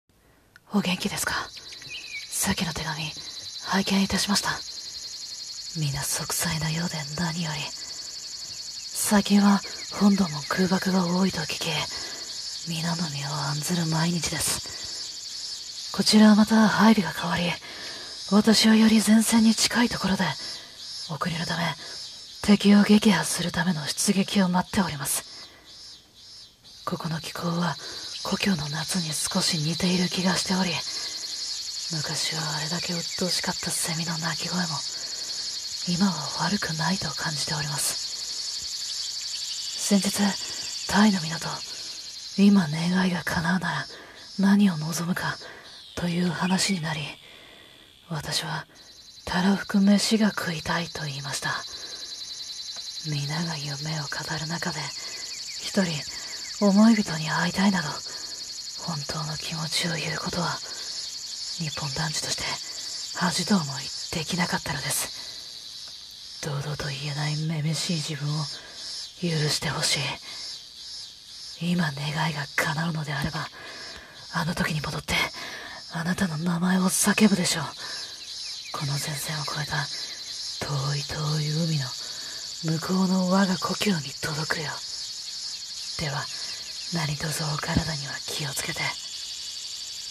【朗読台本】手紙、8月13日付